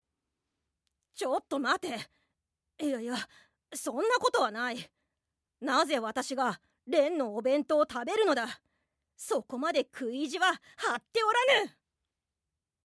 アニメ　１０代